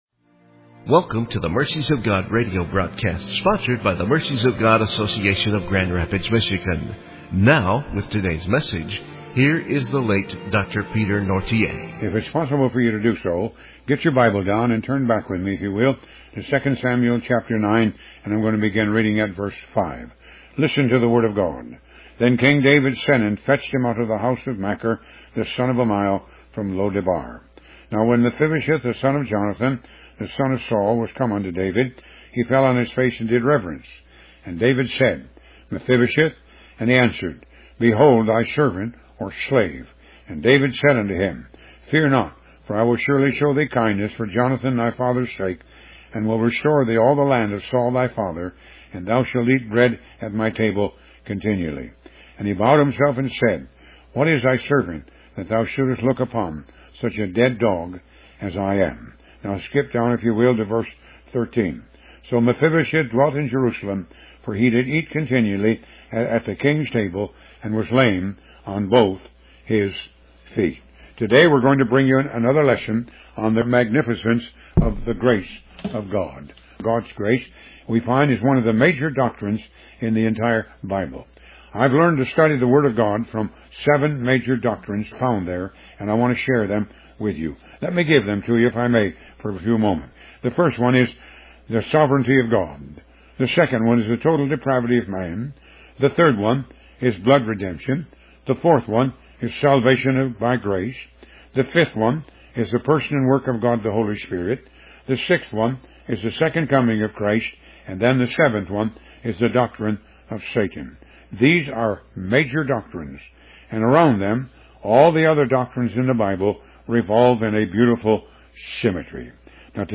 Talk Show Episode, Audio Podcast, Moga - Mercies of God Association and Magnificent Grace on , show guests , about Magnificent Grace, categorized as Health & Lifestyle,History,Love & Relationships,Philosophy,Psychology,Christianity,Inspirational,Motivational,Society and Culture